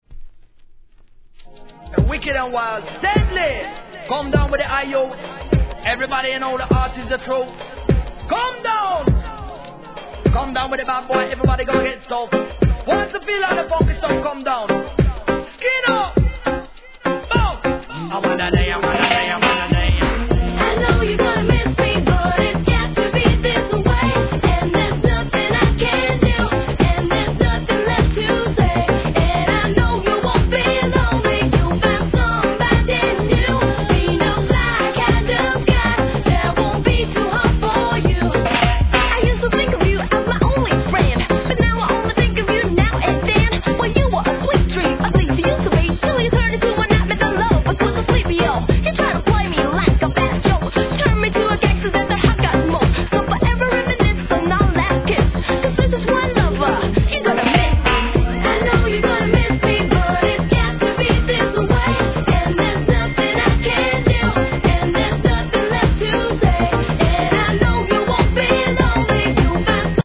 HIP HOP/R&B
POPなダンスナンバーにラガHIP HOP仕立てで怒キャッチーな一曲!